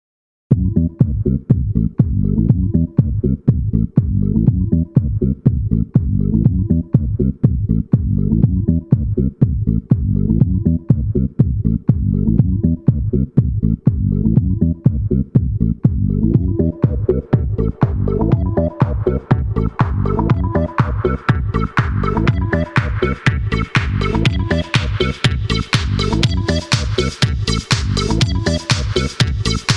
FrenchHouse / Electro / Techno